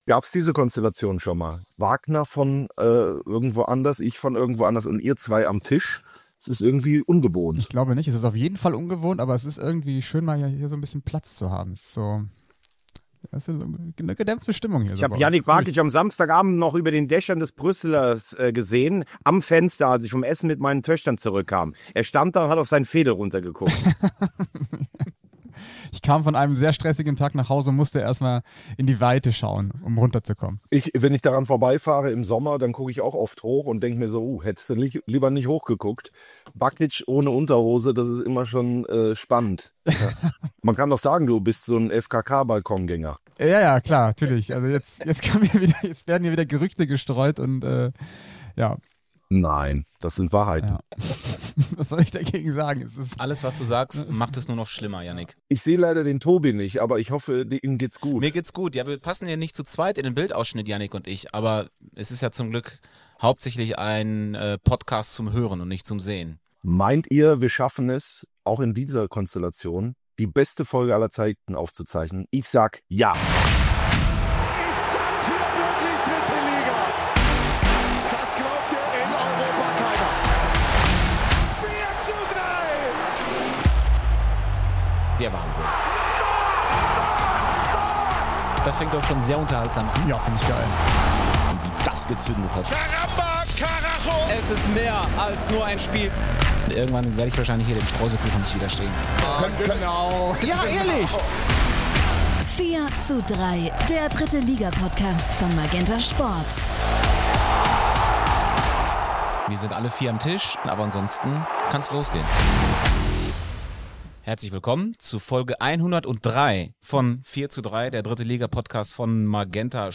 Außerdem stand auch Bundesliga-Schiri Deniz Aytekin für ein Exklusiv-Interview zur Verfügung, um u.a. über seinen Einsatz zuletzt in Aachen zu sprechen. Darüberhinaus geht es in der aktuellen Folge natürlich auch um die anderen Top-Themen der Liga: der Sieg von 1860 gegen Rostock, das brisante Ost-Duell zwischen Aue und Cottbus und das Ausrufezeichen des VfL Osnabrück gegen RW Essen.